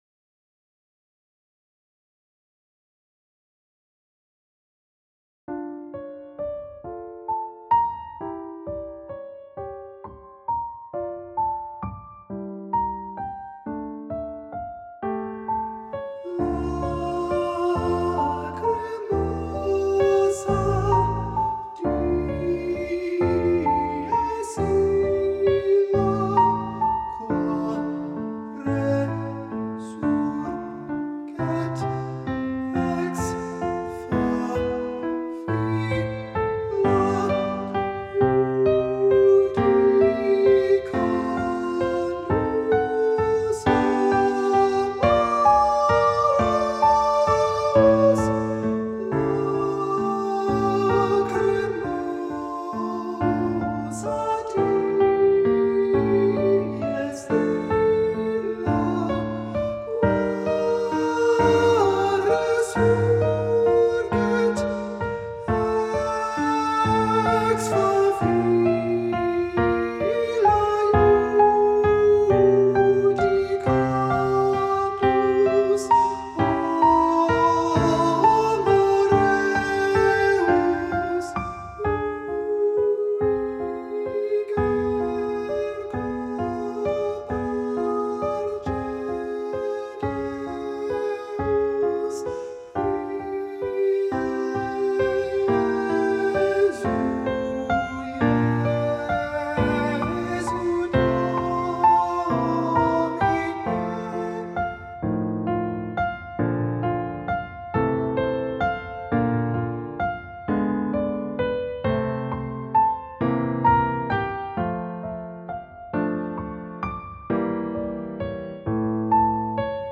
Attached are practice tracks for the Lacrimosa.